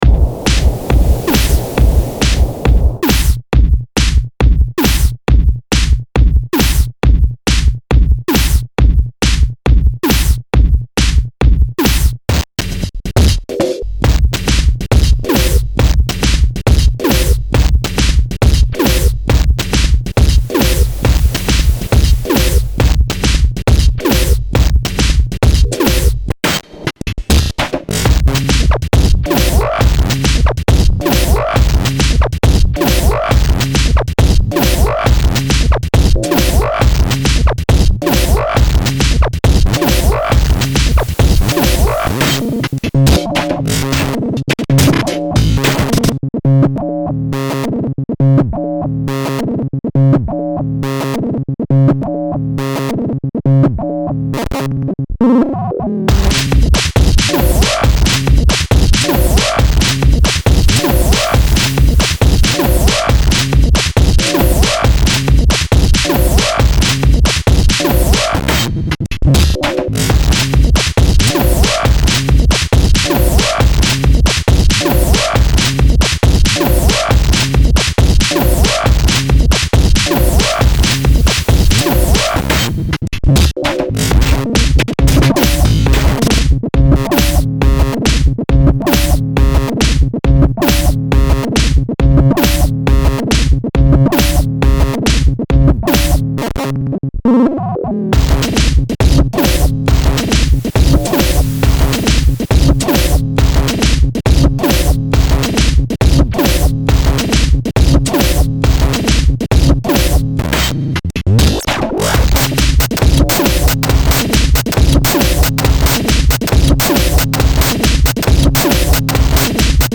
freaky stuff with powerful grooves.